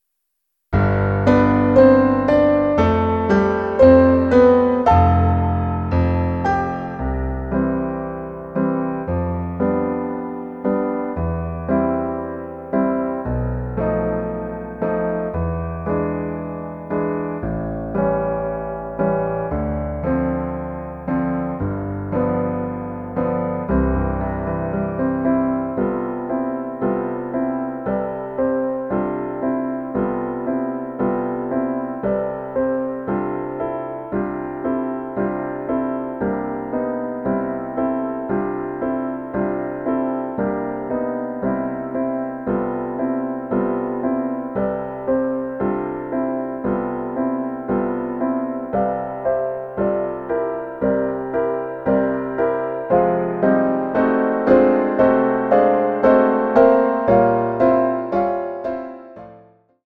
伴奏音源の試聴（約1分）